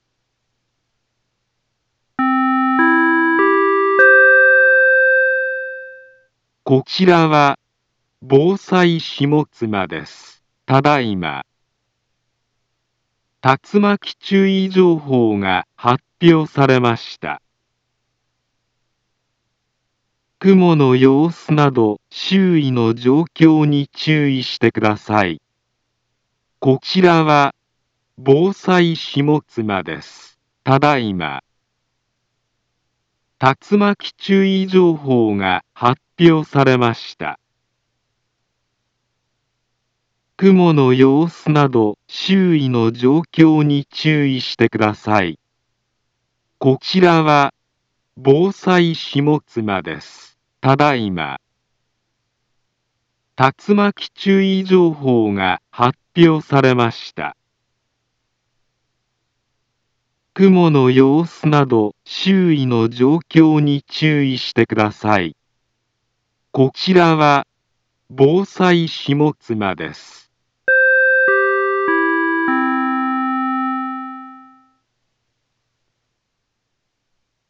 Back Home Ｊアラート情報 音声放送 再生 災害情報 カテゴリ：J-ALERT 登録日時：2024-03-12 18:34:37 インフォメーション：茨城県南部は、竜巻などの激しい突風が発生しやすい気象状況になっています。